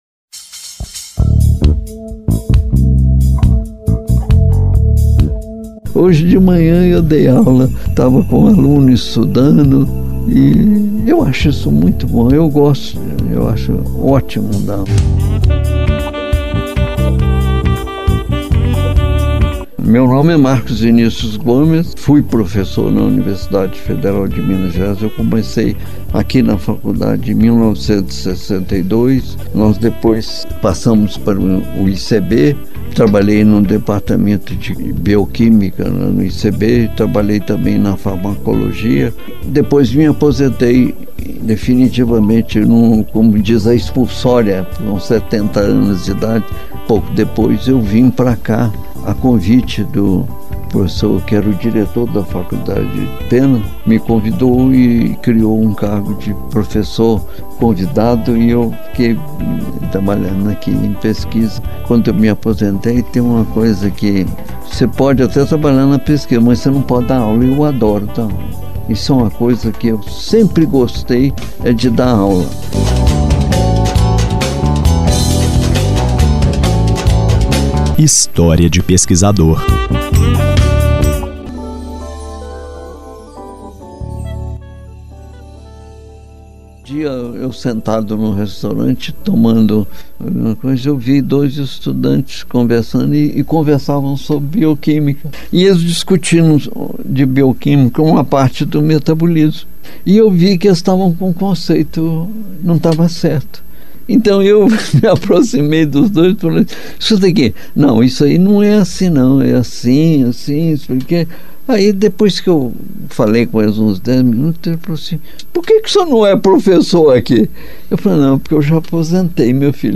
Foi o prenúncio de uma conversa bem-humorada sobre família, referências e hobbies